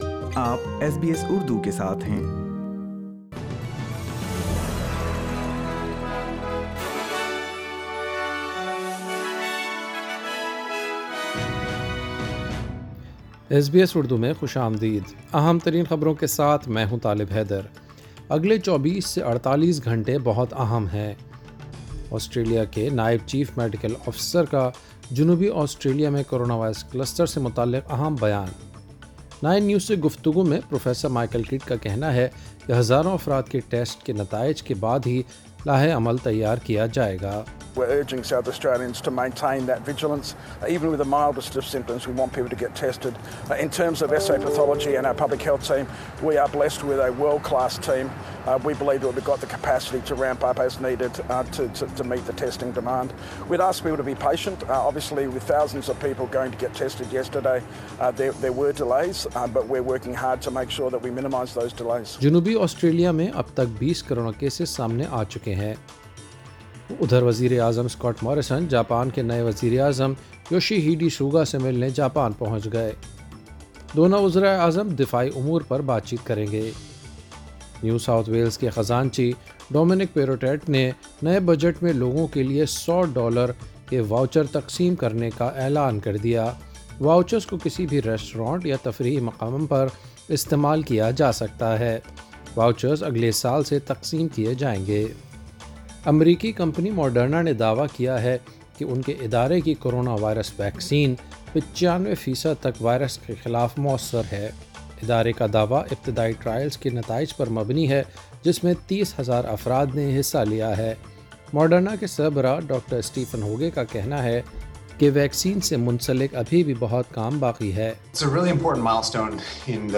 ایس بی ایس اردو خبریں 17 نومبر 2020